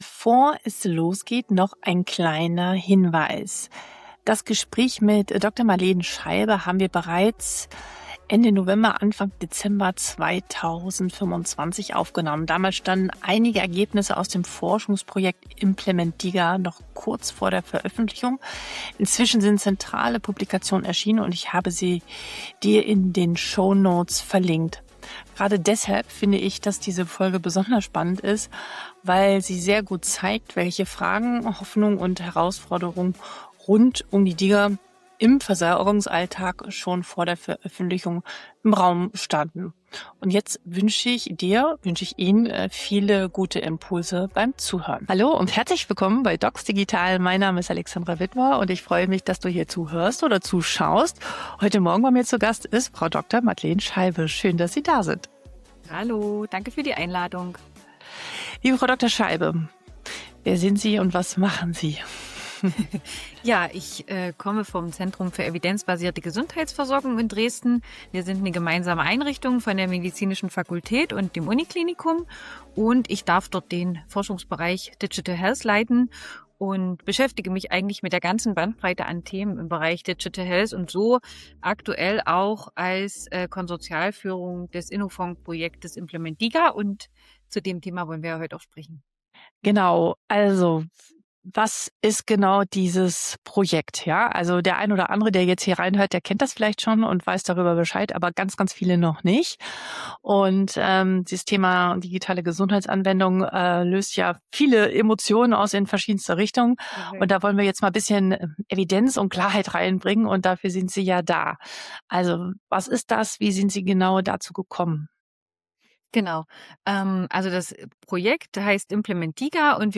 Im Mittelpunkt stehen Fragen zu Evidenz, Versorgungspfaden, Gesundheitskompetenz und zur praktischen Integration von DiGA in die Versorgung. Hinweis: Die Episode wurde Ende November 2025 aufgenommen.